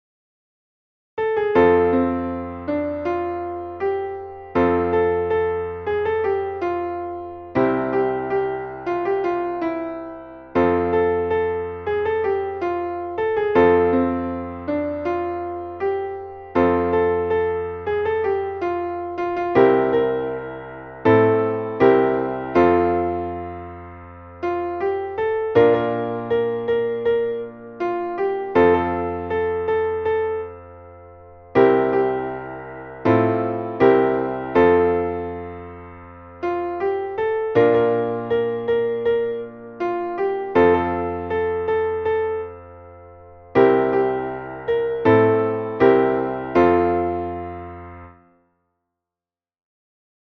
Traditional / Spiritual / Gospel